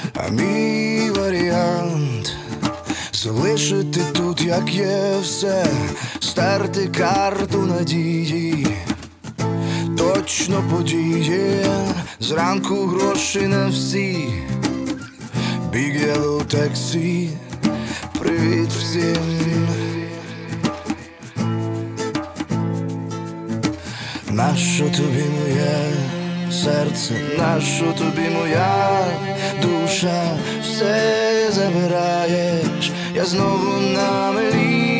Жанр: Рок / Украинский рок / Украинские